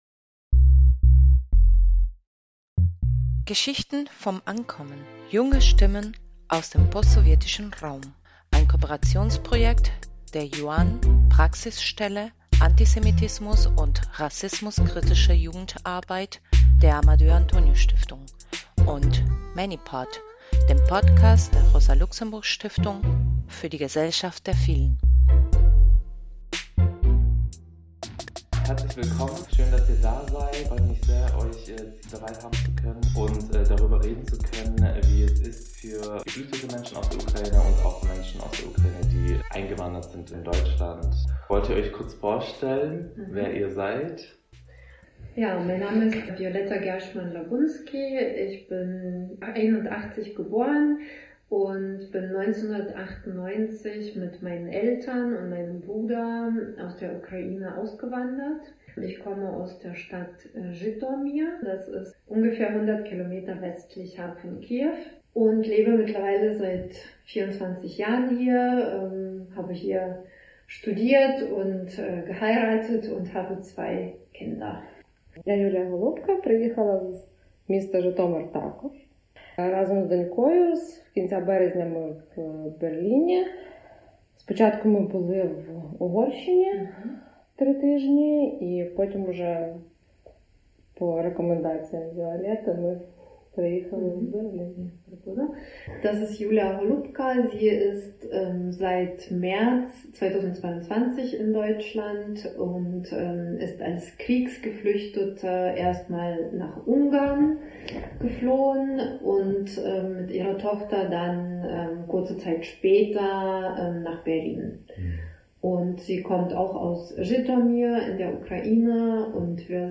Geschichten vom Ankommen: Junge postsowjetische Stimmen im Gespräch #3 ~ Geschichten vom Ankommen Junge postsowjetische Stimmen im Gespräch Podcast